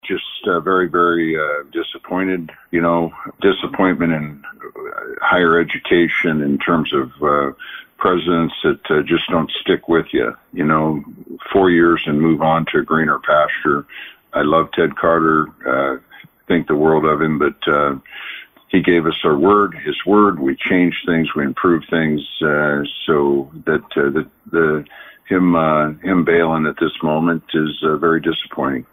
But during a telephone interview with KLIN News Wednesday morning, Pillen says he felt let down by Carter’s departure.
Listen to Pillen’s full comment below.